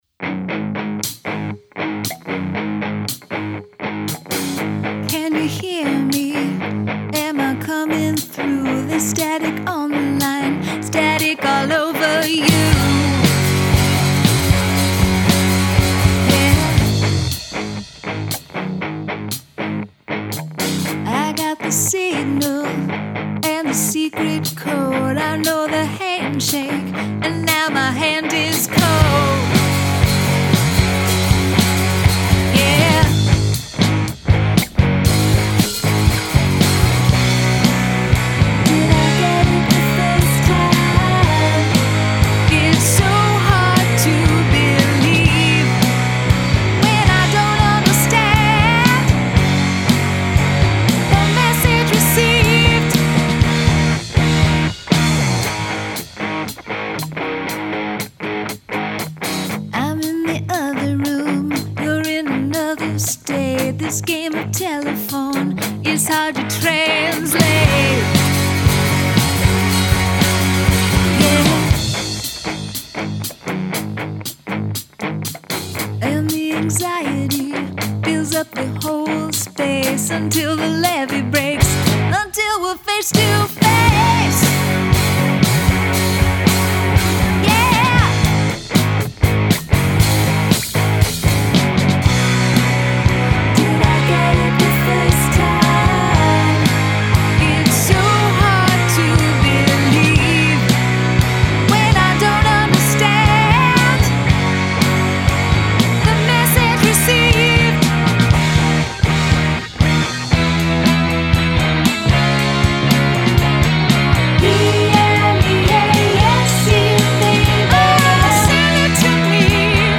Use of spelling in a song.